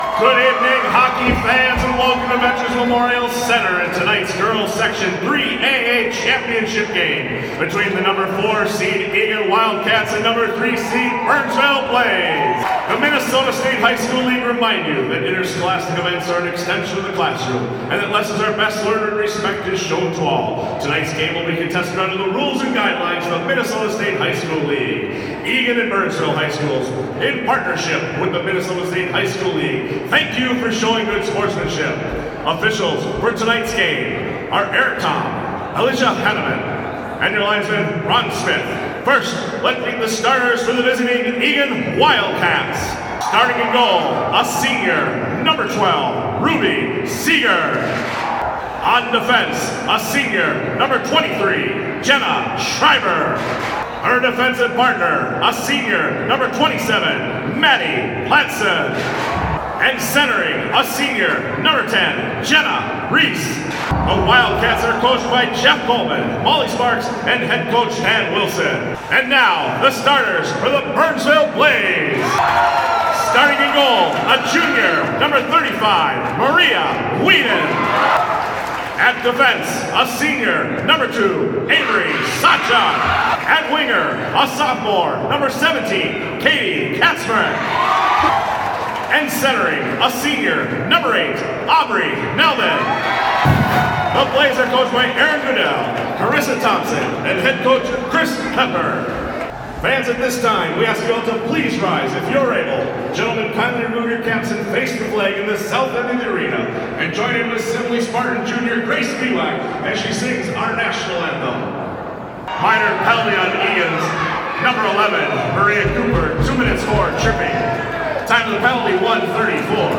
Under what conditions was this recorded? To receive this award, candidates must be nominated by their peers and later, if the nomination is accepted, submit a recorded demo of their best game.